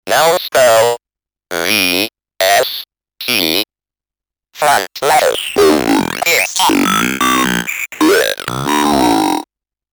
Speak N Spell 80 is a VST plugin instrument for recreating the sound a famous electronic toy from the late 70's and 80's.